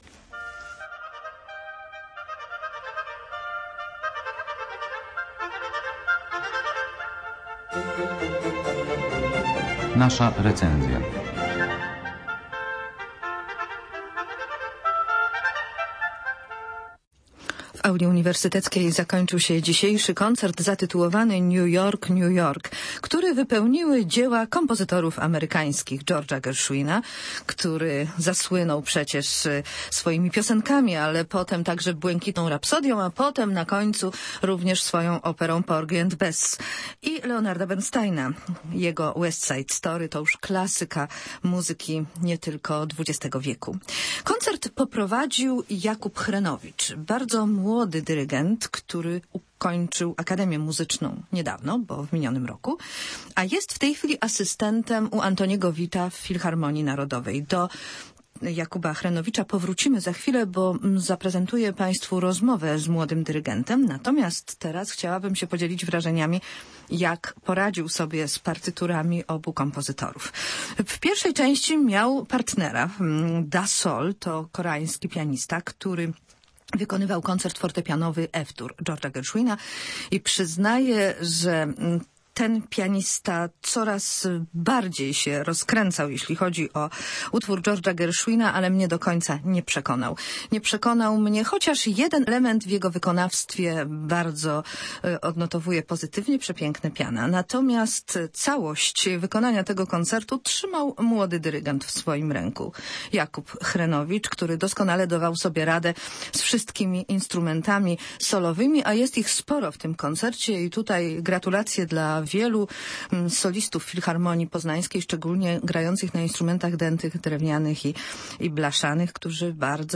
New York, New York - w Auli UAM
pianista
bp47p0euqi9c0sz_new_york_koncert.mp3